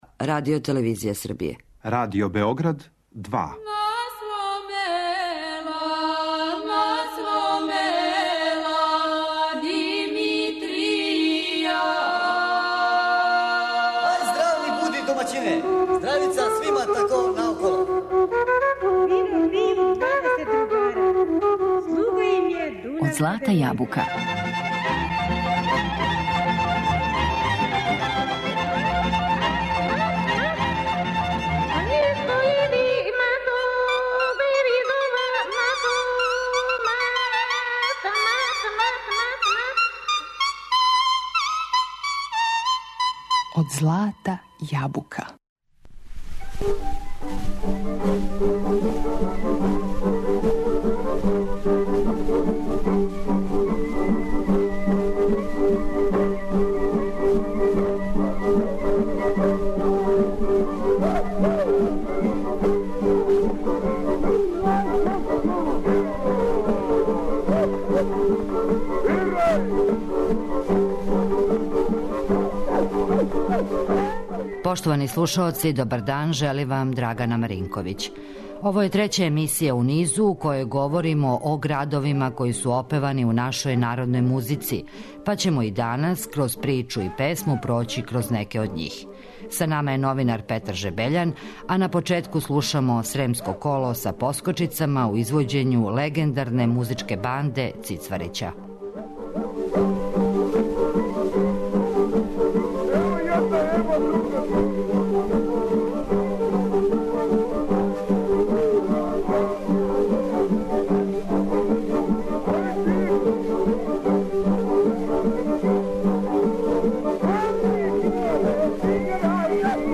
Ово је трећа емисија у низу у којој говоримо о градовима који су опевани у нашој народној музици, па ћемо данас короз причу и песму проћи кроз Шабац, Ниш, Вишеград, Бања Луку и још неке градове.